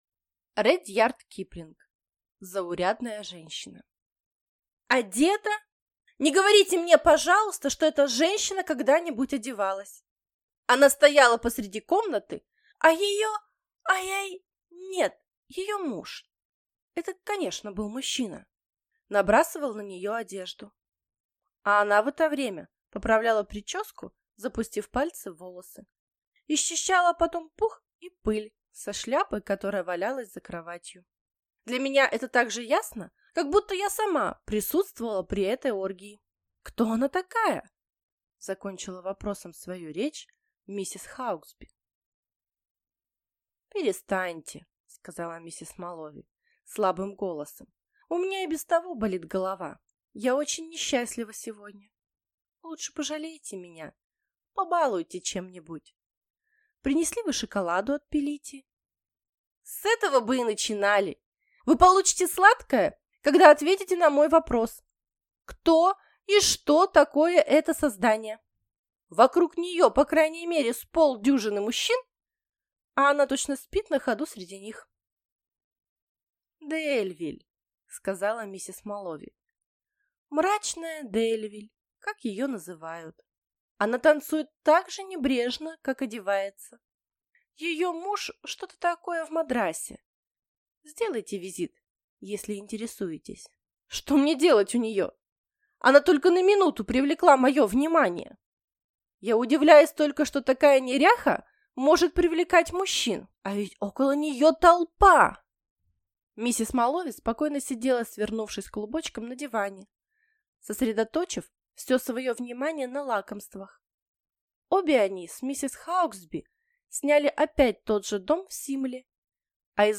Аудиокнига Заурядная женщина | Библиотека аудиокниг